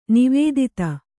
♪ nivēdita